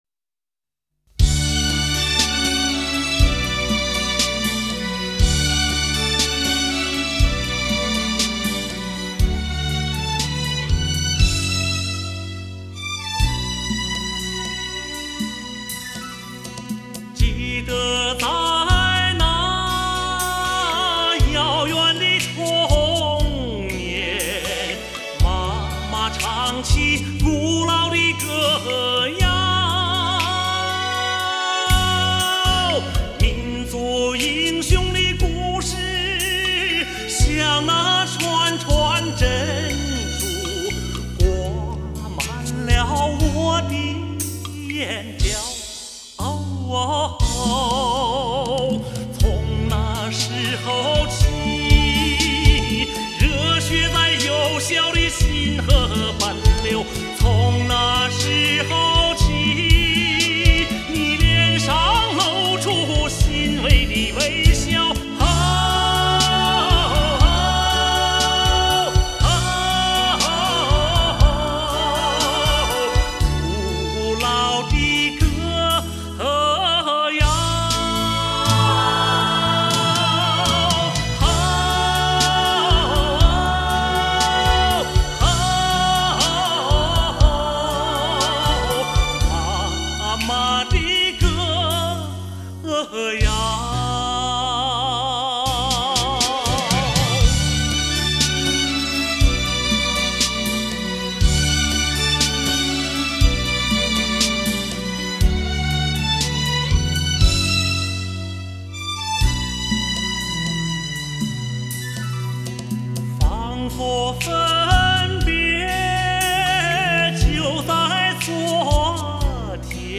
质朴的情感，奔放的激情，难忘的旋律。